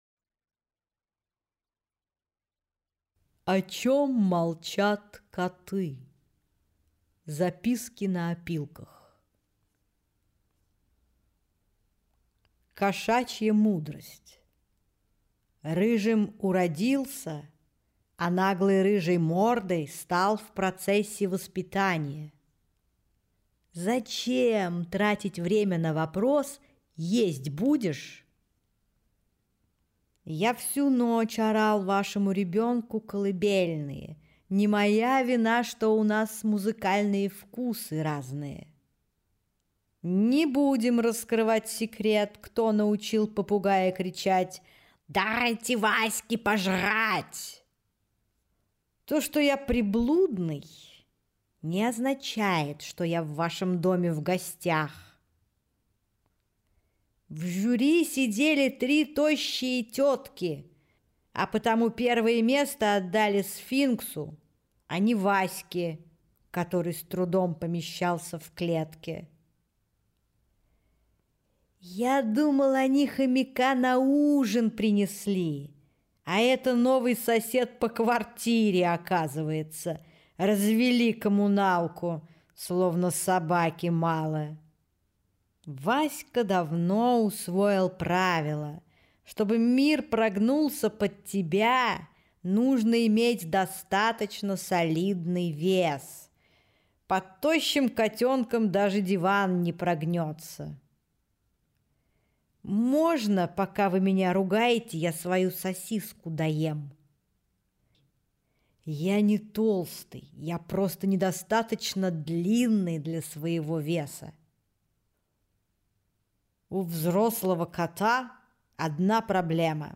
Аудиокнига О чем молчат коты. Записки на опилках | Библиотека аудиокниг